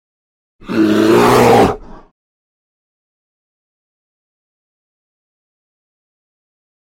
Звуки медведей
Одинокий рык гризли